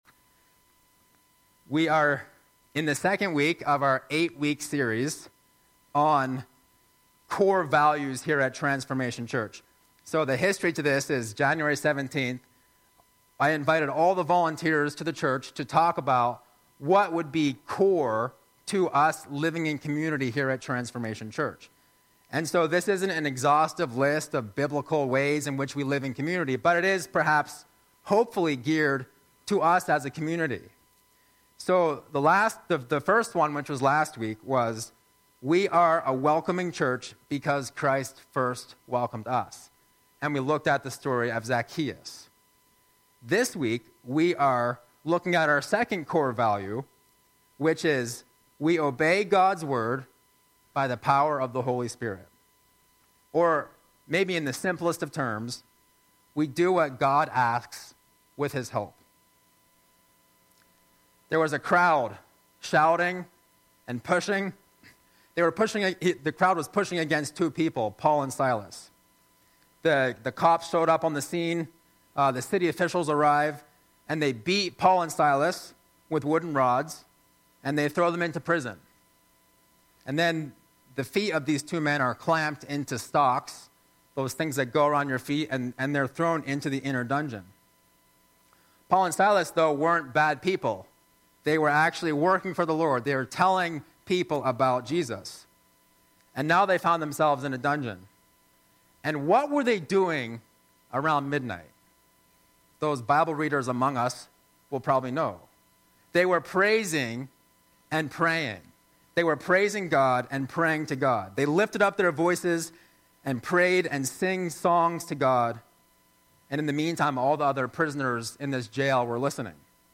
Sunday Messages | Transformation Church